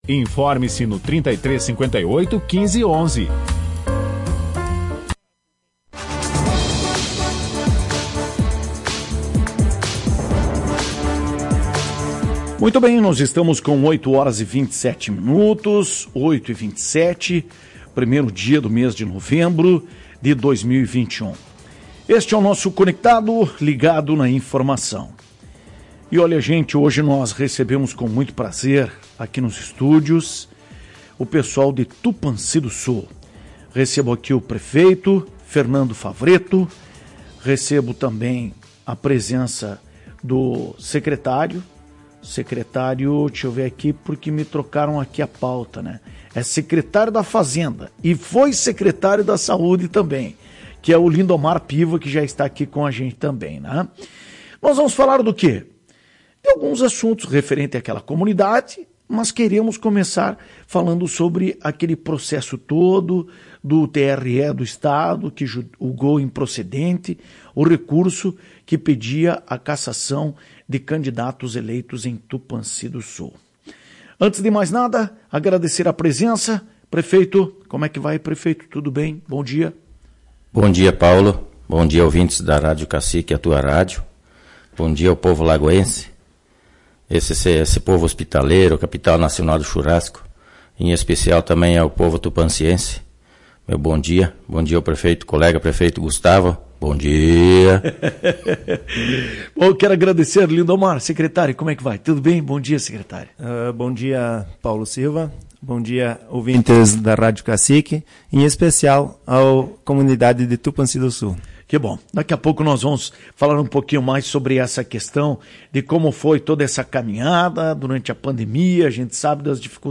Em entrevista à Tua Rádio Cacique na manhã desta segunda-feira, 01 de novembro, o prefeito de Tupanci do Sul, Fernando Luís Favretto (PP), comemorou a vitória na Justiça de um processo movido pela coligação derrotada nas eleições de 2020, que alegava compra de votos.